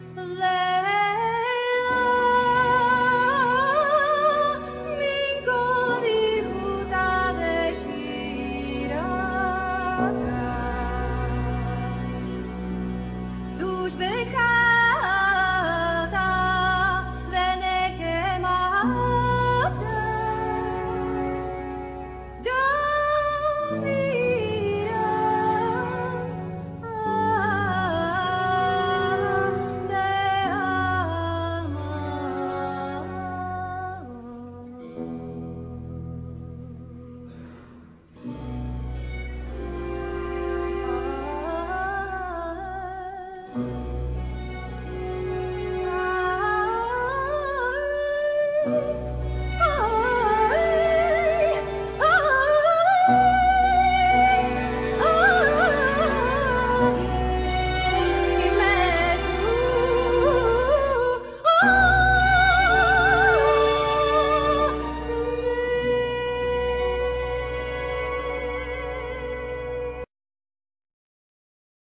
Vocals,Violin
Winds,Bass,Percussions,etc